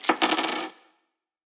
来自我的卧室的声音" 硬币掉在木桌上(冻结)
描述：在Ableton中录制并略微修改的声音